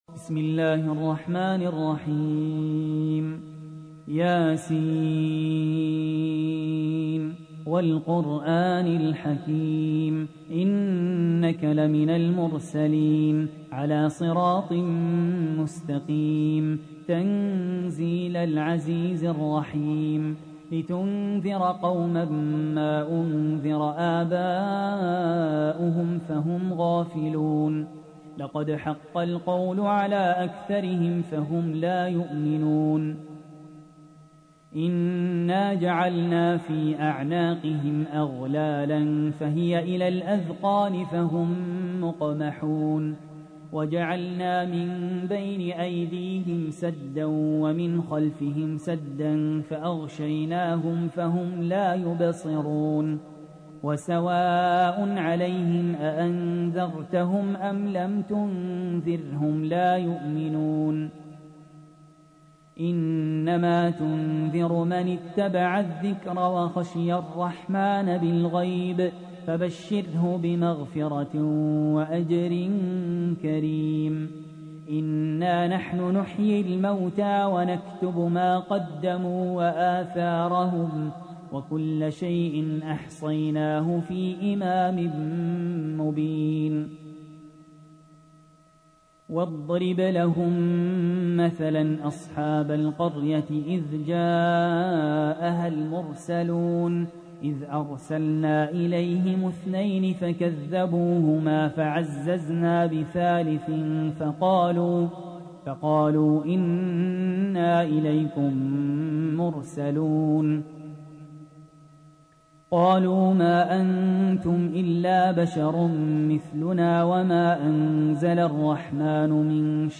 تحميل : 36. سورة يس / القارئ سهل ياسين / القرآن الكريم / موقع يا حسين